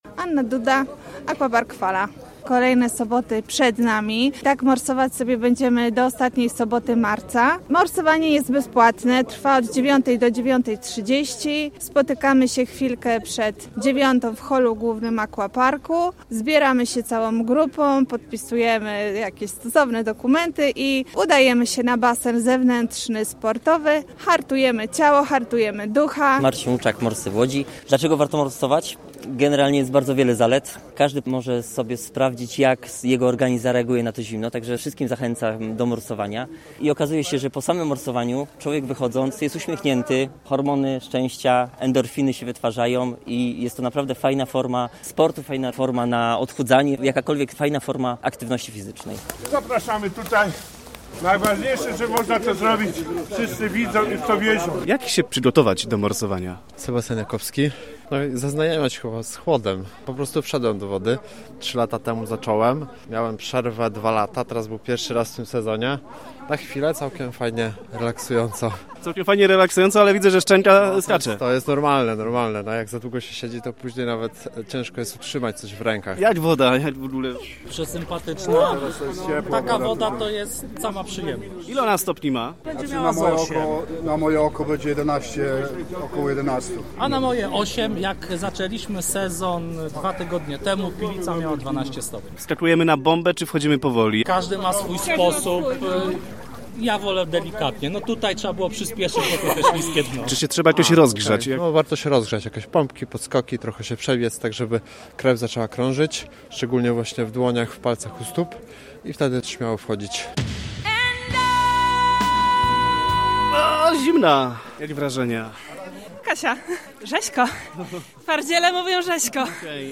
W łódzkim Orientarium, na wybiegu niedźwiedzi malajskich, miłośnicy morsowania zainaugurowali oficjalnie sezon 2023/2024.